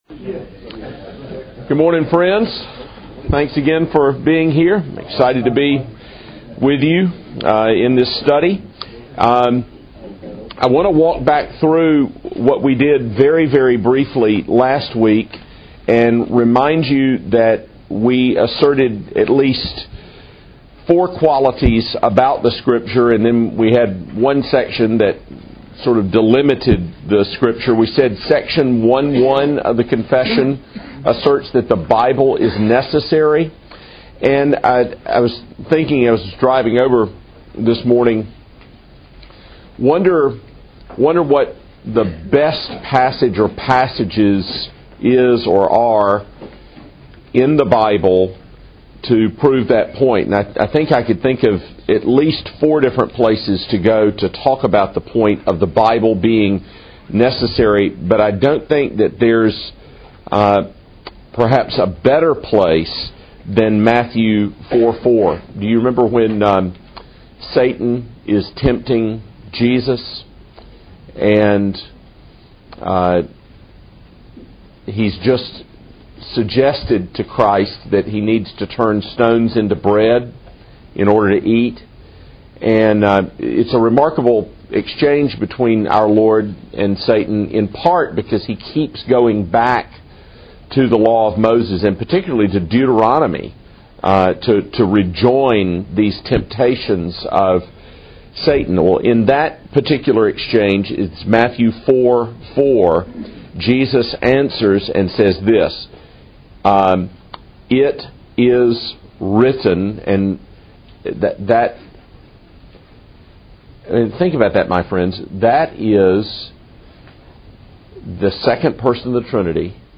WCF_Lecture2.mp3